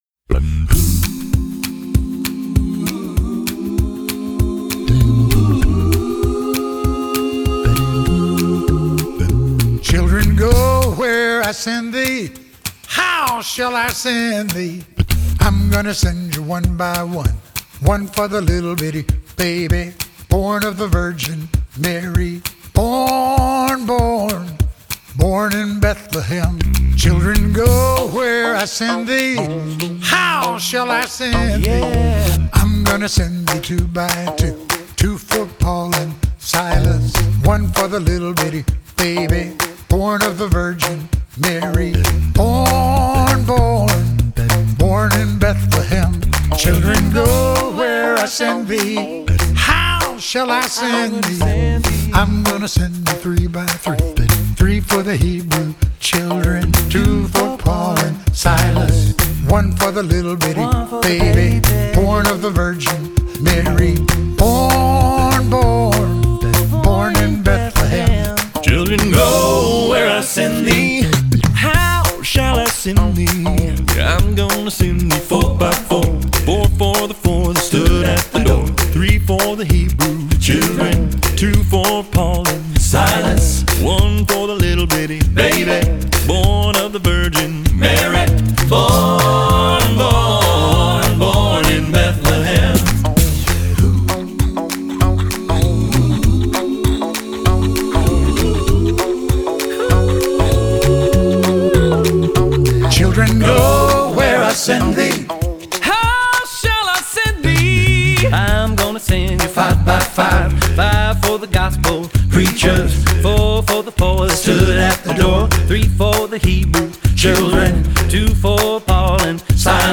американская акапелла-группа, состоящая из пяти вокалистов